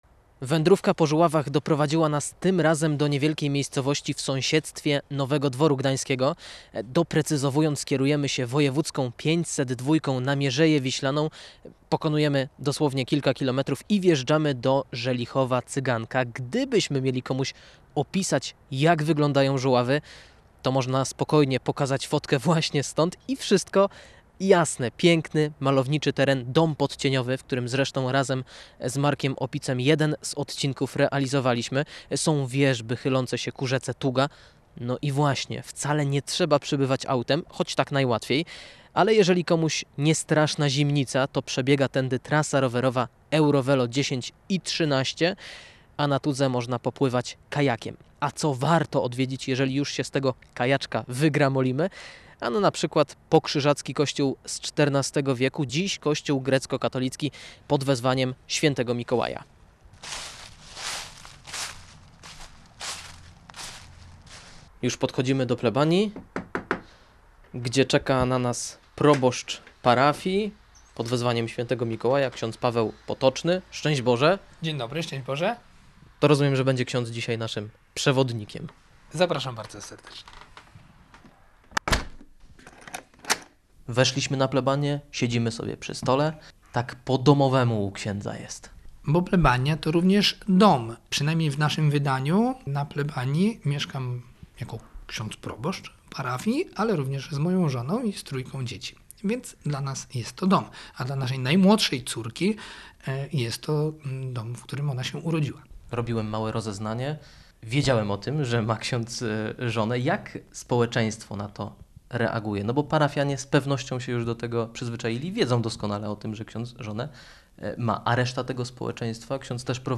W audycji „Nad rozlewiskiem” odwiedziliśmy uroczy XIV-wieczny kościółek. Żuławskie świątynie mają w sobie coś wyjątkowego: choć niewielkie, zachwycają klimatem i charakterystyczną architekturą.